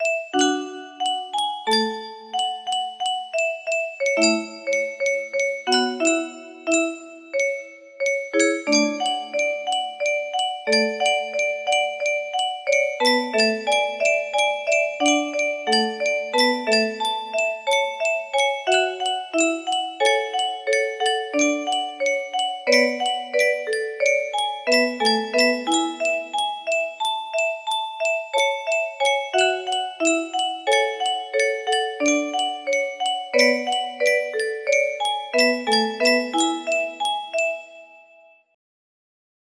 prova music box melody